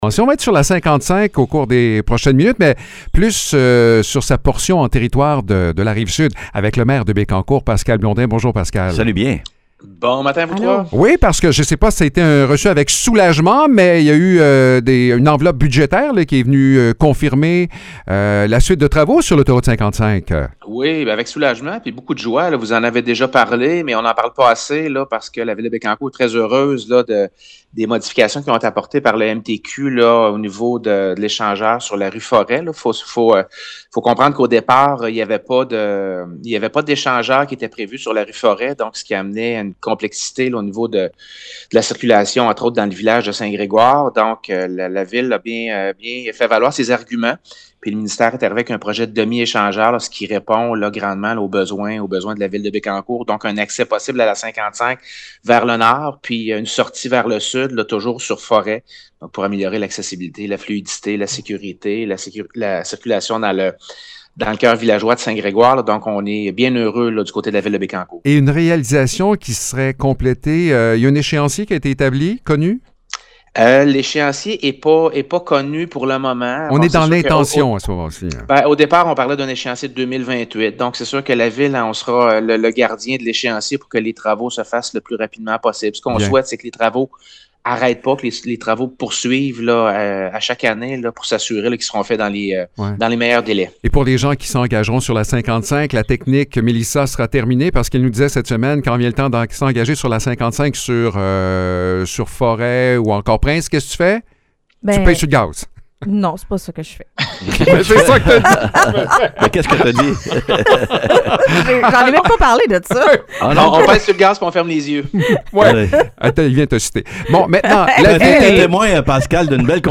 Pascal Blondin, maire de Bécancour, fait le point sur la suite des travaux sur l’autoroute 55 et les prochaines étapes à venir.